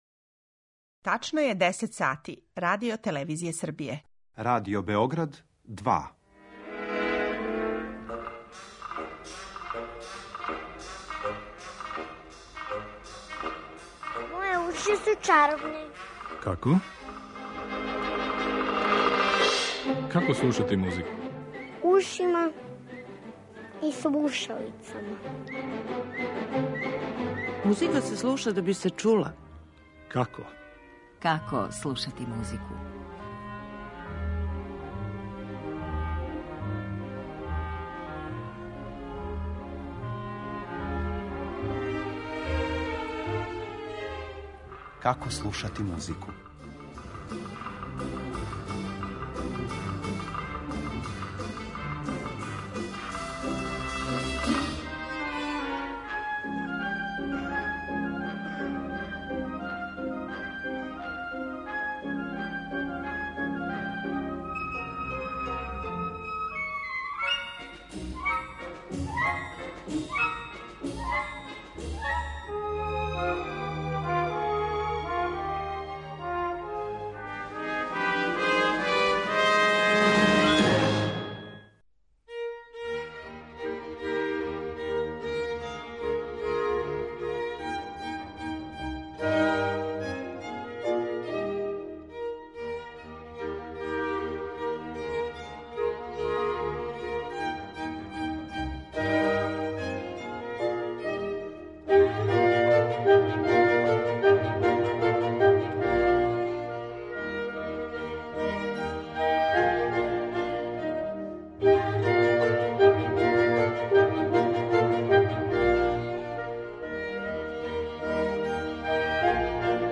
Највише пажње поклонићемо руској традицији и начинима на које је обогатила уметничку музику делима Глинке, Мусоргског, Бородина и Чајковског, а чућете и примере са осталих европских меридијана, док ћемо последњу емисију циклуса посветити само народним играма и плесовима.